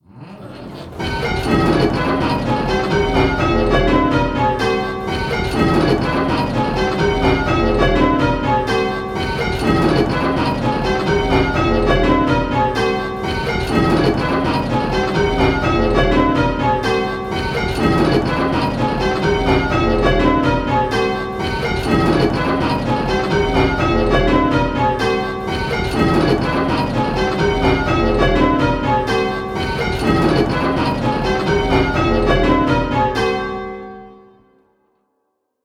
Striking 8 Bell Rounds - Pebworth Bells
Striking 8 Bell Rounds - Round 1